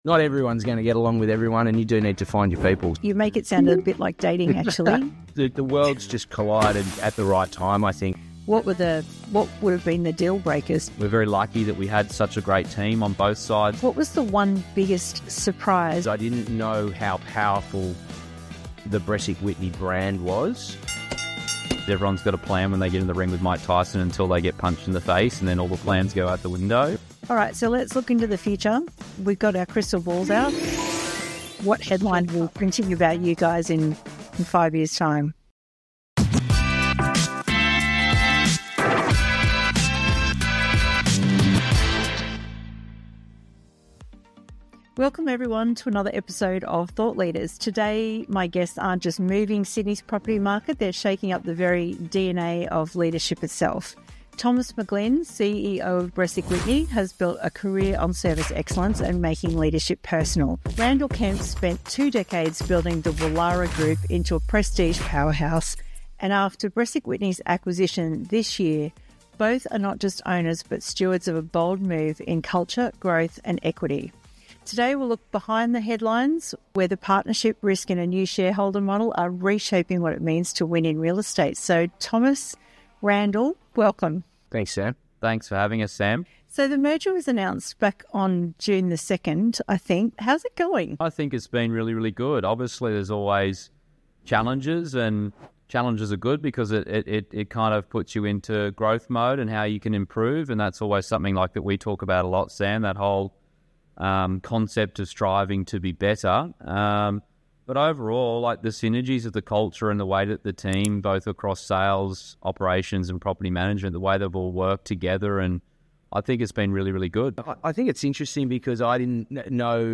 In this raw conversation